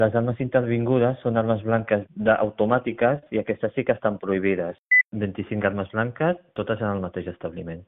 El resultat l’ha confirmat el tinent d’Alcaldia de Seguretat, Soufian Laroussi, en declaracions a Ràdio Calella TV.